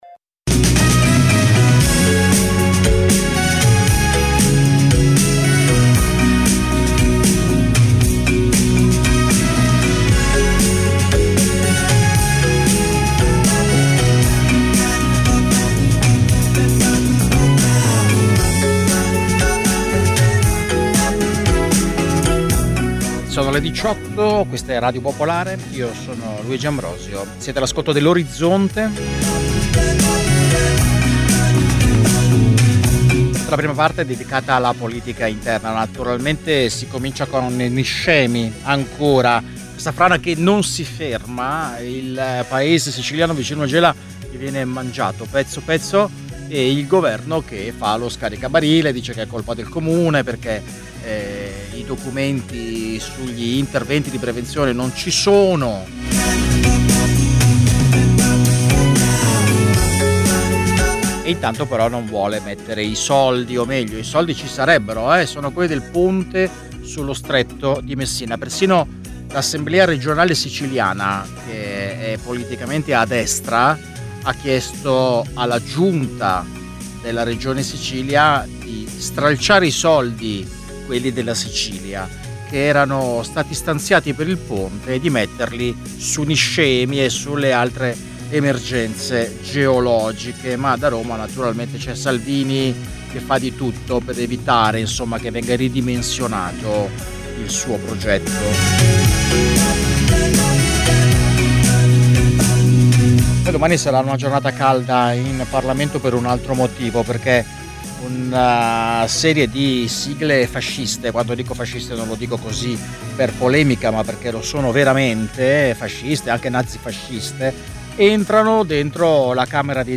Dalle 18 alle 19 i fatti dall’Italia e dal mondo, mentre accadono. Una cronaca in movimento, tra studio, corrispondenze e territorio. Senza copioni e in presa diretta.